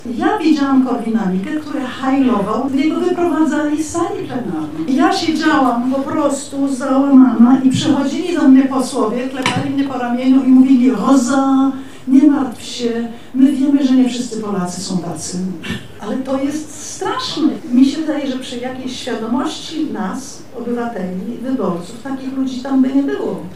Róża von Thun– mówi Róża von Thun.